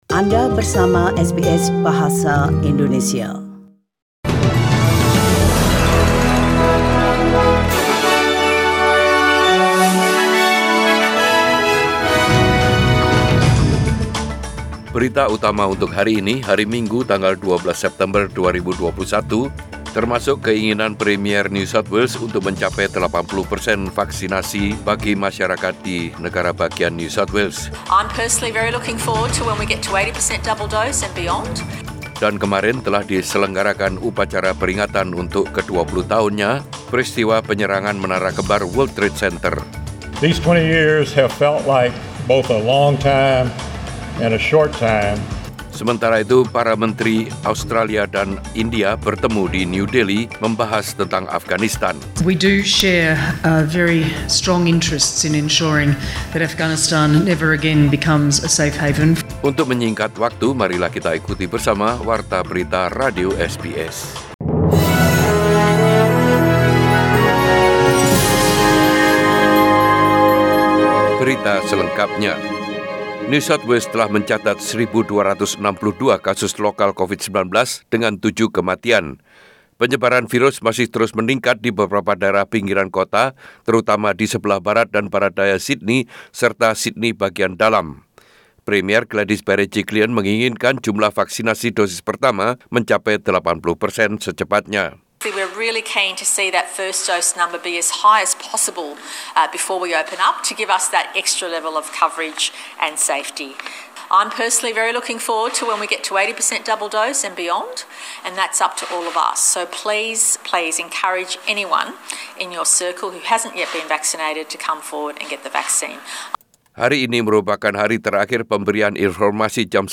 SBS Radio News in Bahasa Indonesia - 12 September 2021
Warta Berita Radio SBS Program Bahasa Indonesia Source: SBS